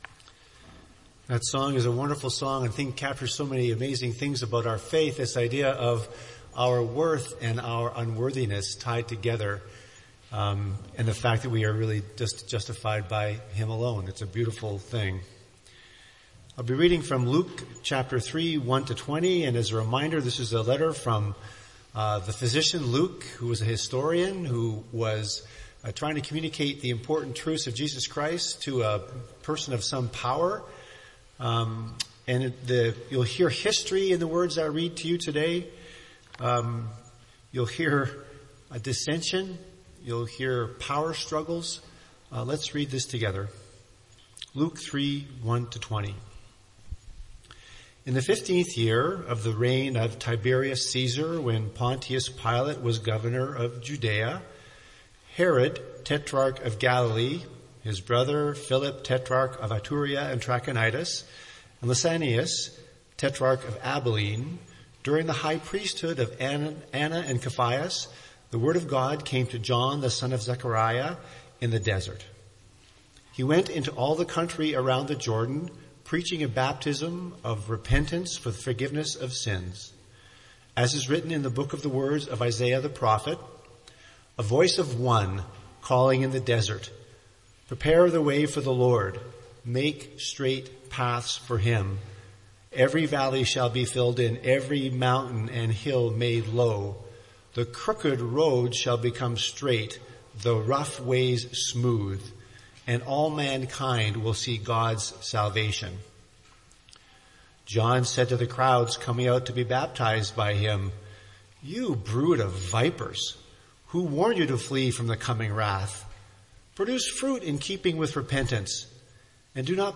MP3 File Size: 17.0 MB Listen to Sermon: Download/Play Sermon MP3